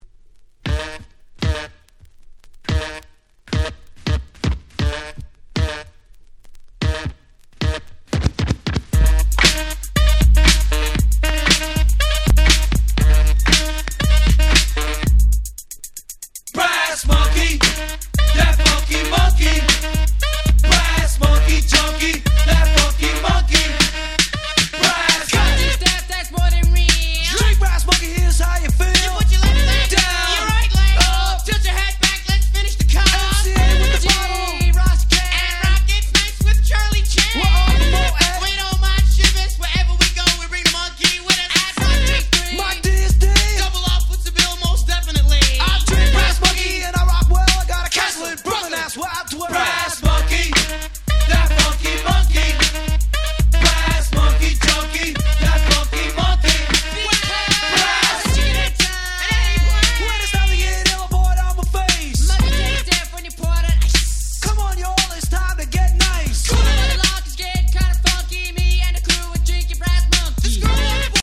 87' Super Hit Hip Hop !!
Old School